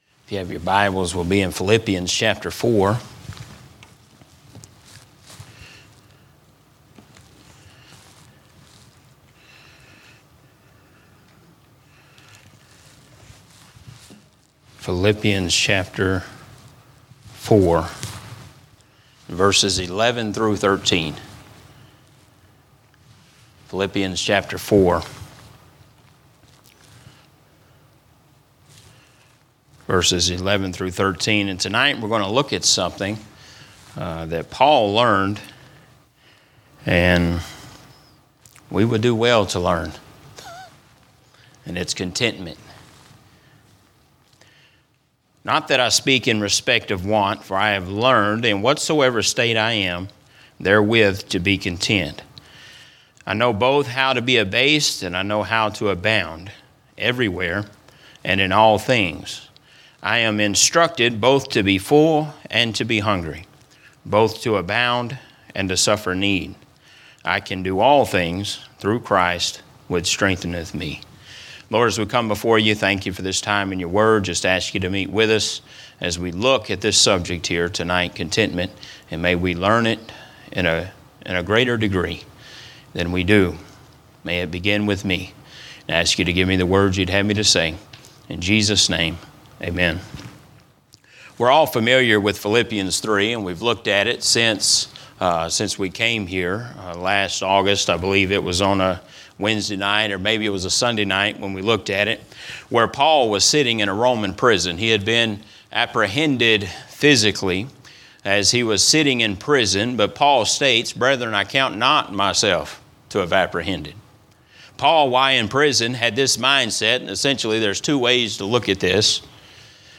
A message from the series "General Preaching."